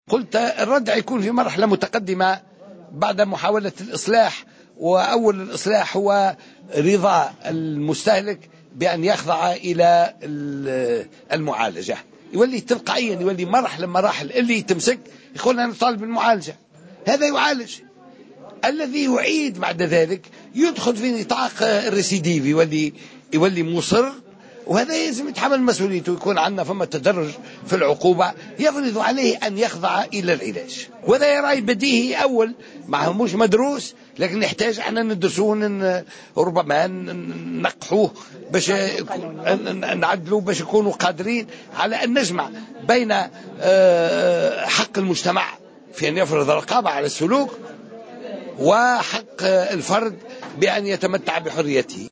وأكد مورو في تصريح لمراسل الجوهرة أف أم، على هامش حلقة نقاش حول مشروع القانون المتعلق بالمخدرات، عُقدت اليوم السبت، على ضرورة تعديل القانون الحالي المتعلق بالمخدرات، ليكون قادرا على الجمع بين حق المجتمع في أن يفرض رقابة على السلوك وحق الفرد في حريته، وفق تعبيره.